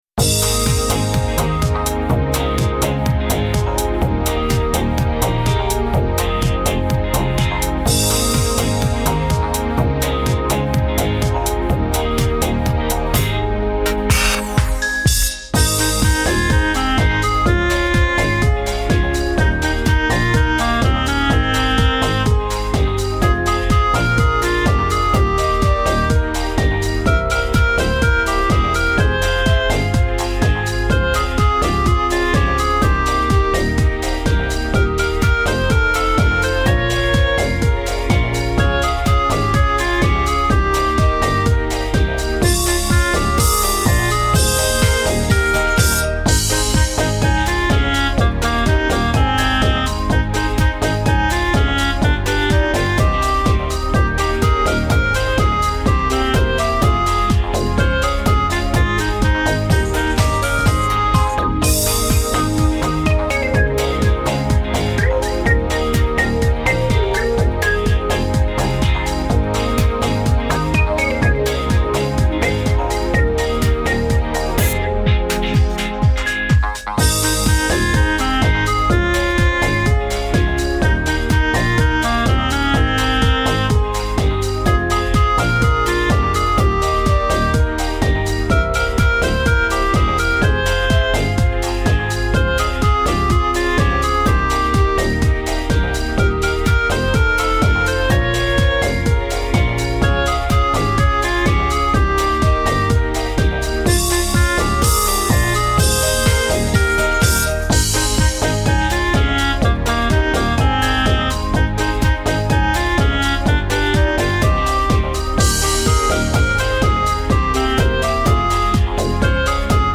• Категория: Детские песни
караоке